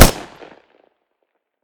smg-shot-02.ogg